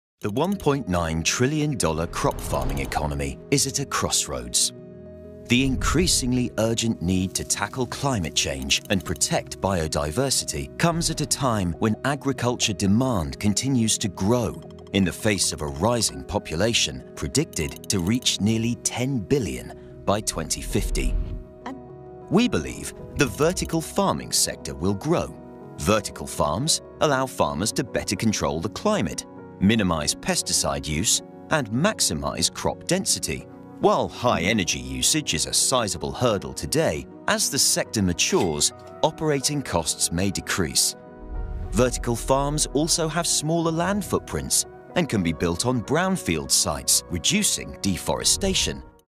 Vídeos corporativos
Micrófono de válvula de condensador cardioide Sontronics Aria